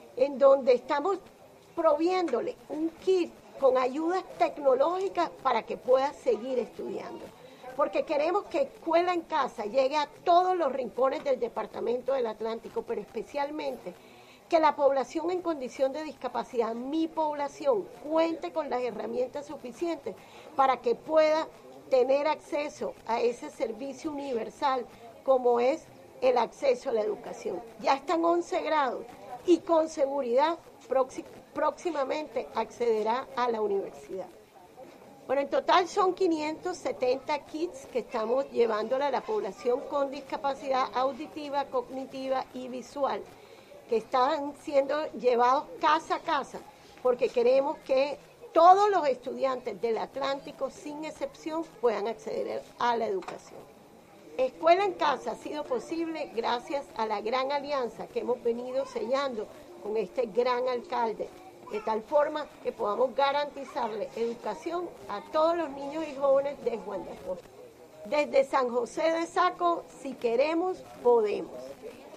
VOZ-GOBERNADORA-TABLETS-online-audio-converter.com_.mp3